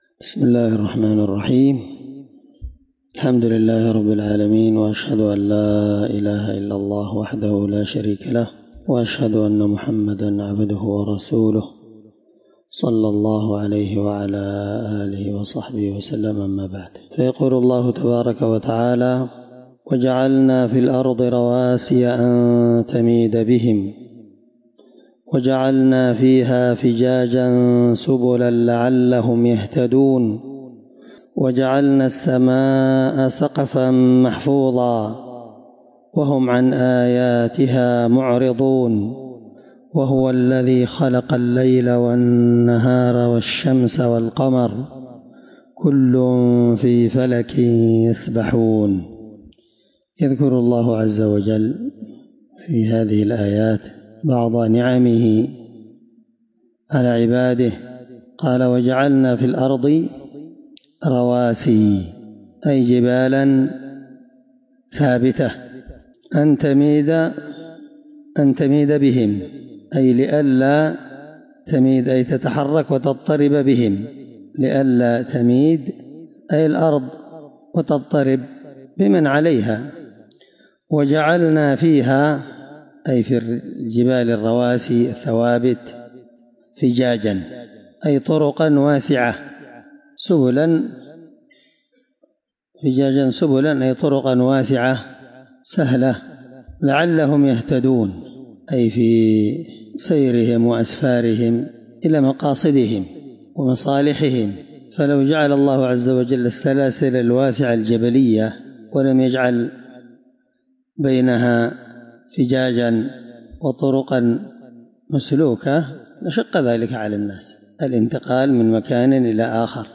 الدرس11تفسير آية (31-33) من سورة الأنبياء
21سورة الأنبياء مع قراءة لتفسير السعدي